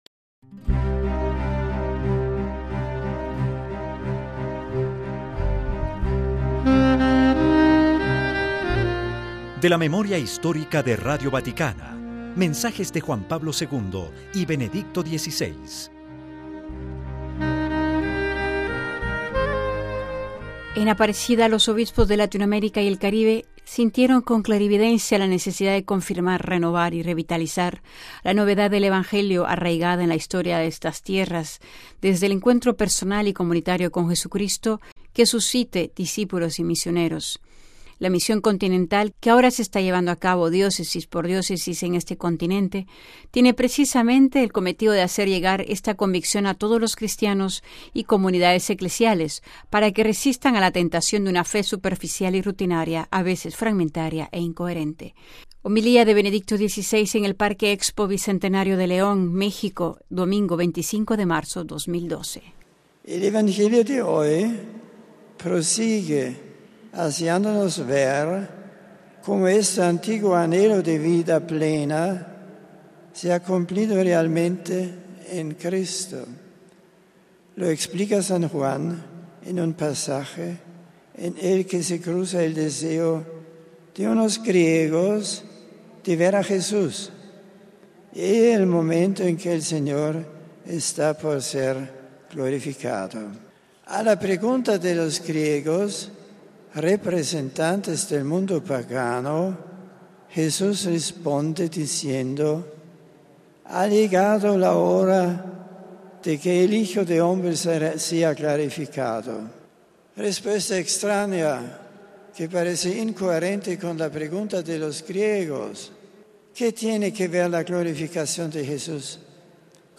MP3 Benedicto XVI, en su viaje realizado a México y Cuba, en marzo de este año, hablando del Santuario del Cubilete, en México, monumento a Cristo Rey, dijo que su reinado no consiste en el poder de sus ejércitos para someter a los demás, se funda en un poder más grande que gana los corazones: el amor de Dios que él ha traído al mundo con su sacrificio y la verdad de la que ha dado testimonio.